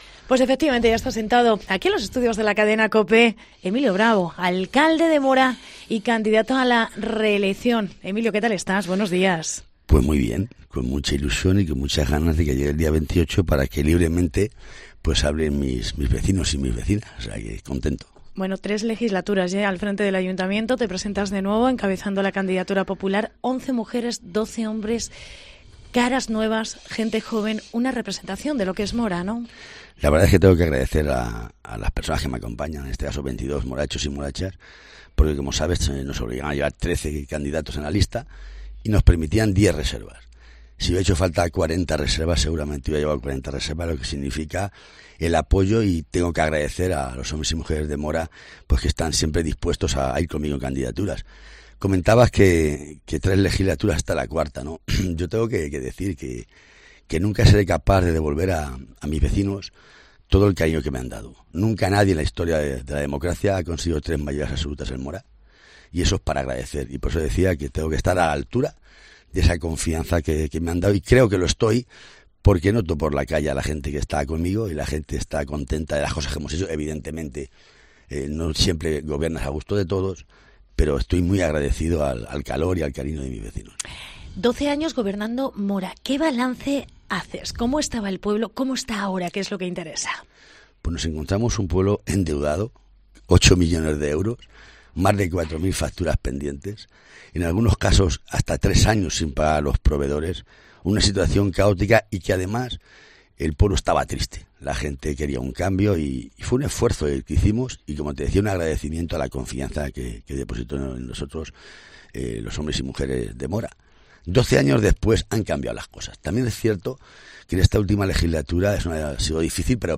Hoy ha pasado por nuestro espacio electoral el alcalde de Mora y candidato a la reelección por el PP Emilio Bravo (PINCHA AQUÍ PARA ESCUCHAR LA ENTREVISTA)